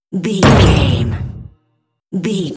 Cinematic drum stab hit trailer debris
Sound Effects
Atonal
heavy
intense
dark
aggressive